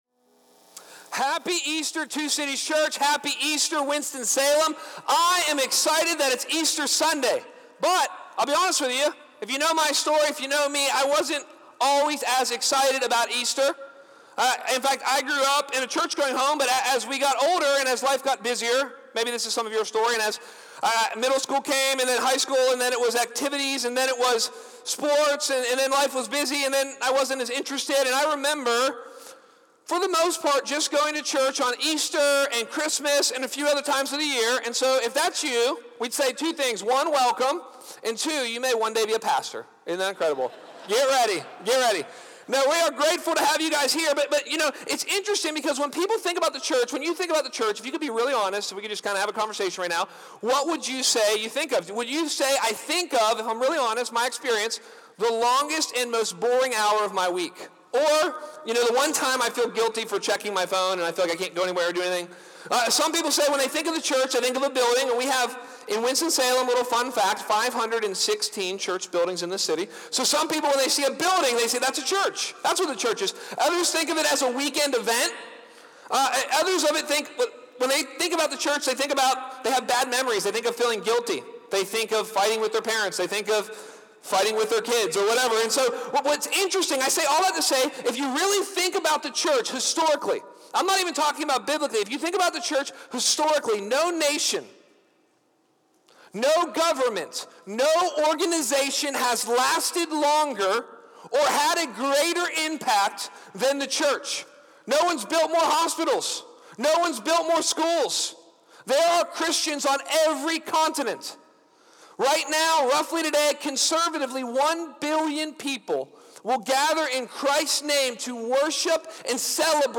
What is repentance? *Note: We apologize for the quality of the audio. It was pulled from a source that glitched out during recording.